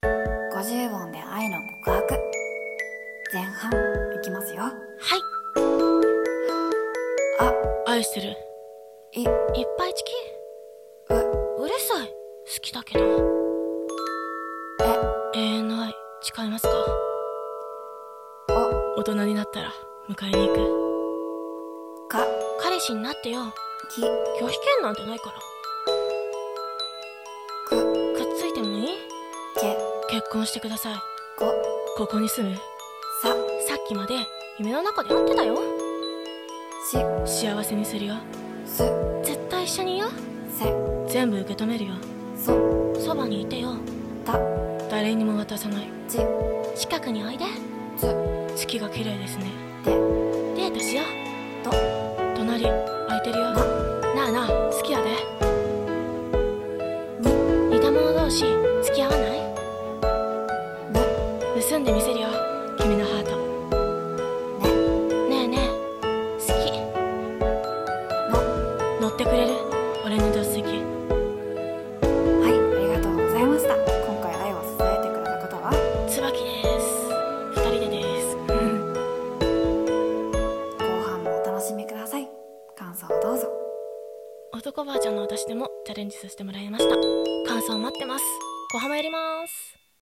[声面接] 50音で愛の告白 [演技力]